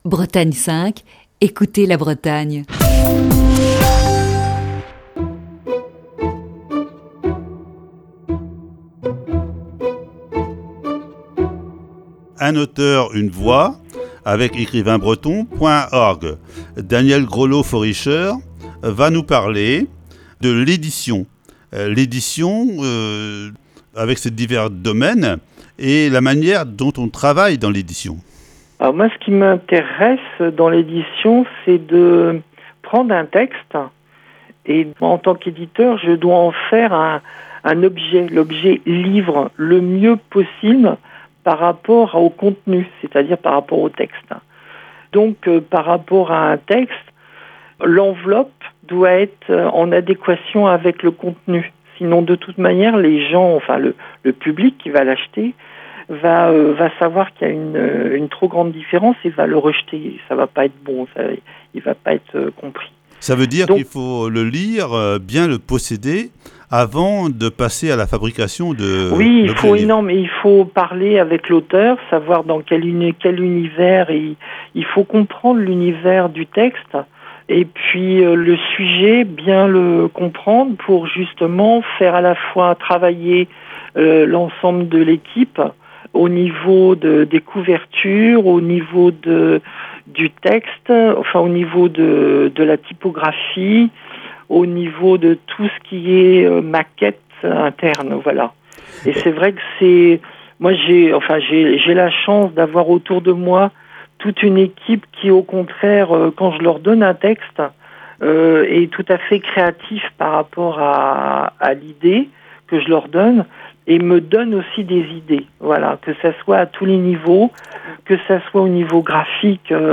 Voici ce mercredi la troisième partie de cette série d'entretiens.